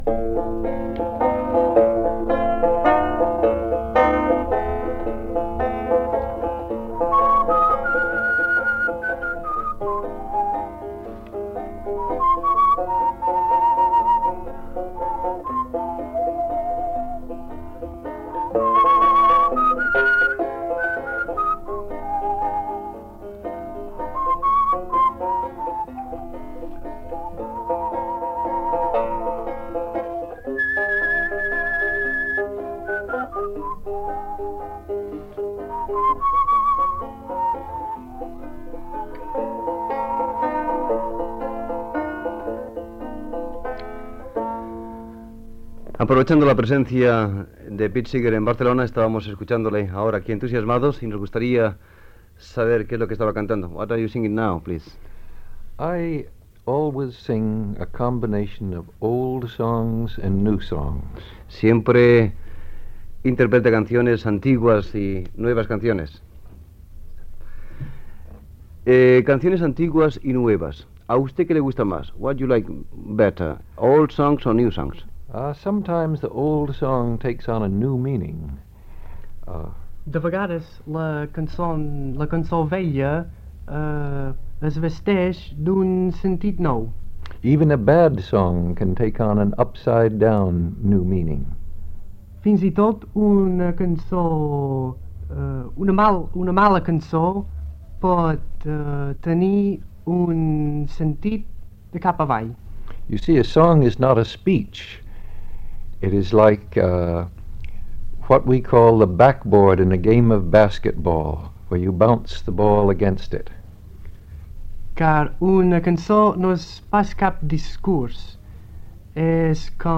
Música instrumental i entrevista al cantant Pete Seeger, que parla sobre les seves cançons i interpreta un tema en castellà, en directe (el cantant va actuar a la Festa del Treball, durant les Festes de la Mercè de Barcelona de 1980)
Entreteniment